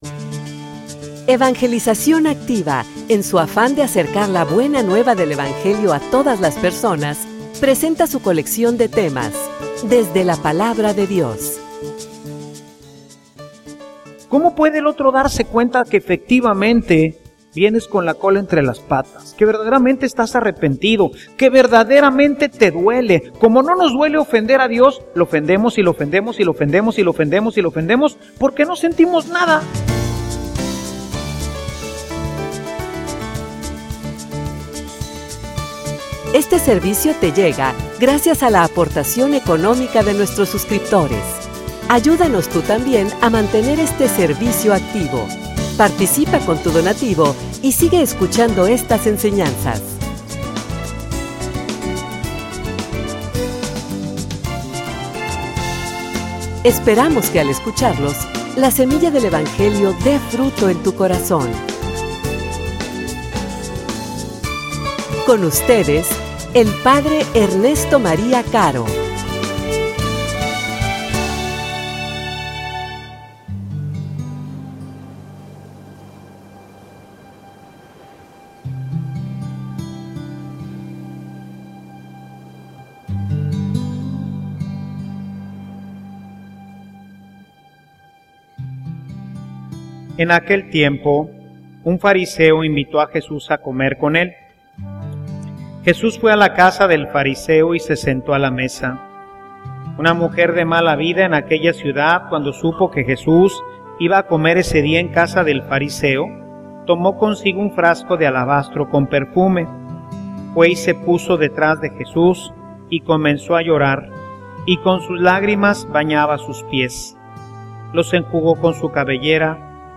homilia_La_reconciliacion_y_sus_signos.mp3